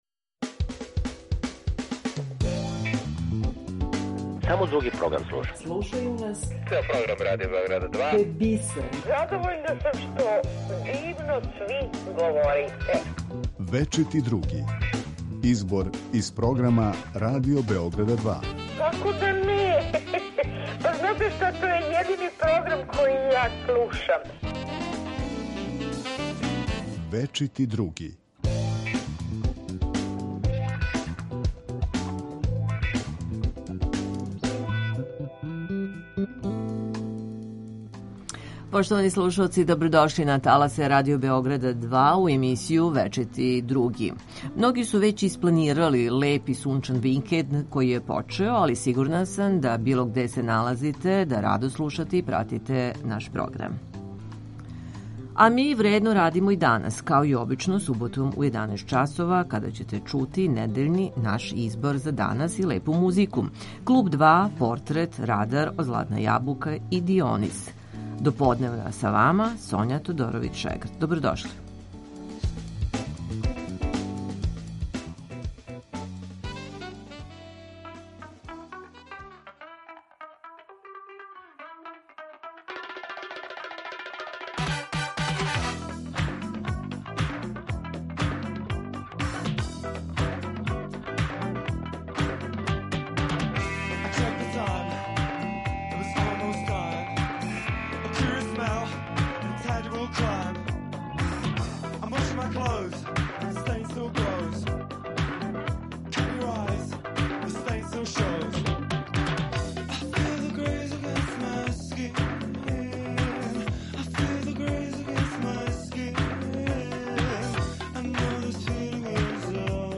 У емисији Вечити Други чућете избор из програма Радио Београда 2.